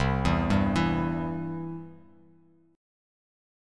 C Chord
Listen to C strummed